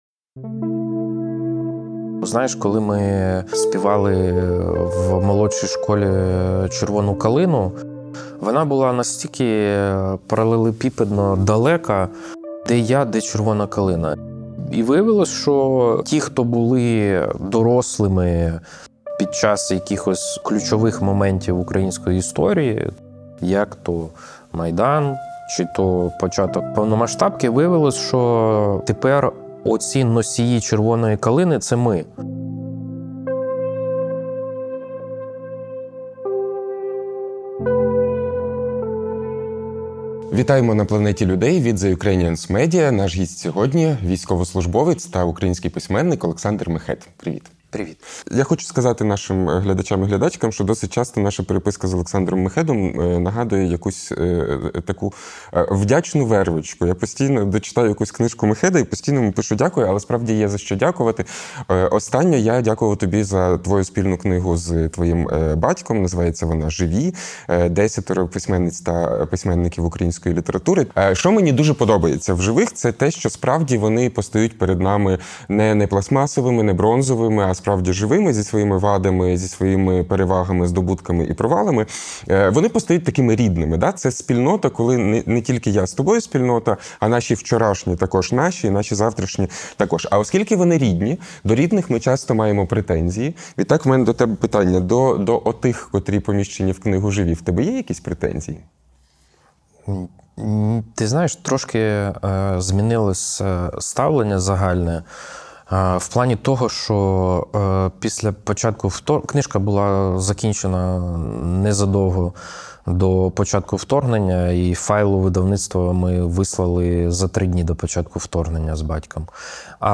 Це розмова про те, як війна змінює оптику: від сприйняття власного дому до ставлення до ворожої культури.